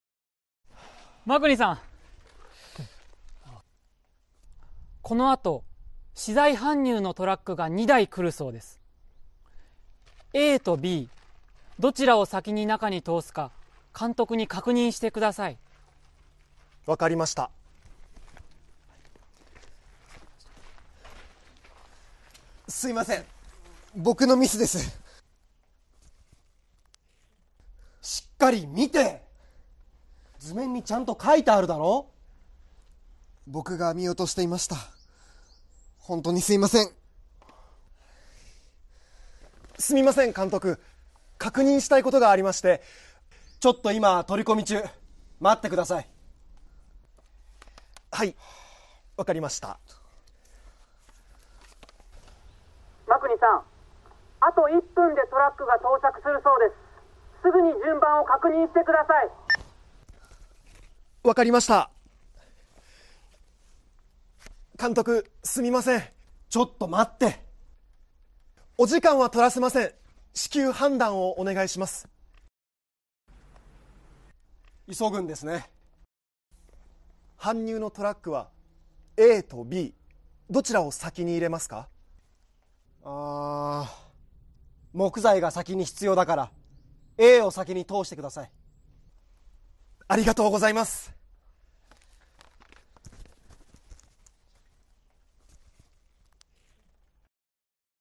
Role-play Setup